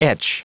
Prononciation du mot : etch
etch.wav